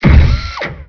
step3.wav